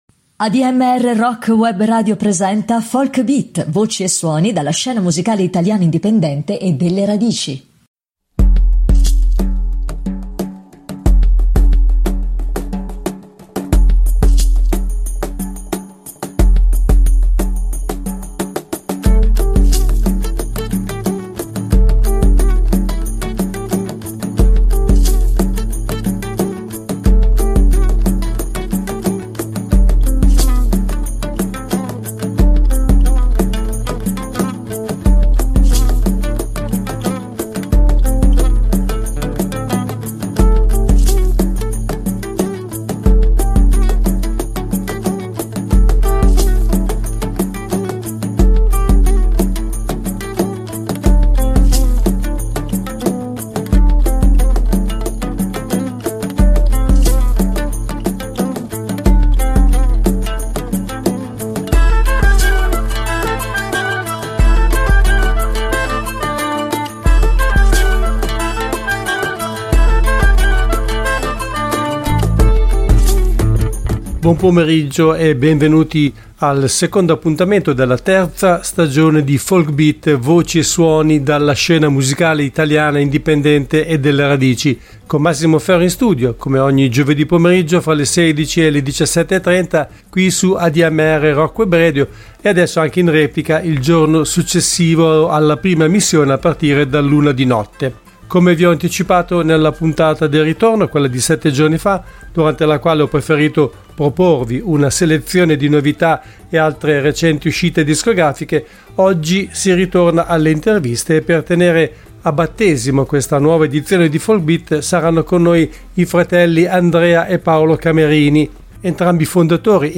Ospite del programma al telefono